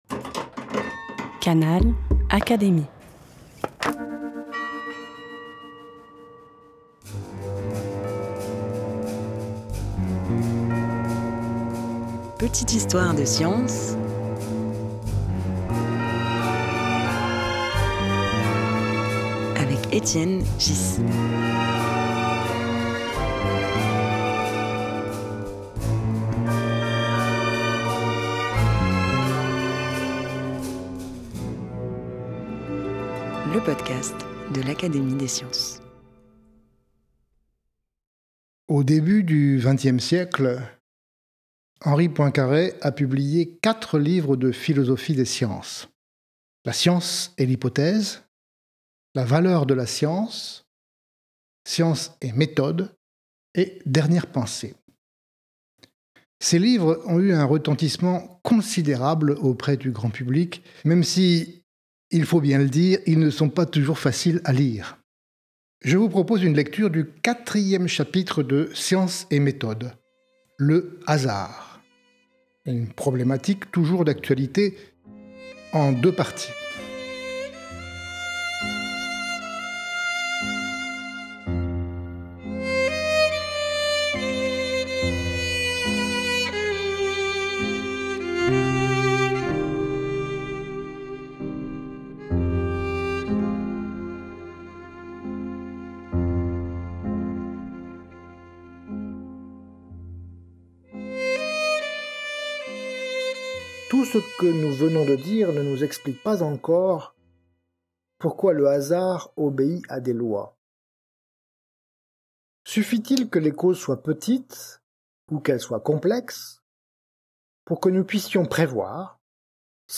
Extrait lu par Étienne Ghys, Secrétaire perpétuel de l’Académie des sciences, tiré du livre Science et Méthode, par Henri Poincaré (1908).